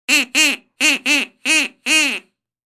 Goofy Duck Quack Sound Effect
Description: Funny and amusing duck call sound, repeated several times.
Goofy-duck-quack-sound-effect.mp3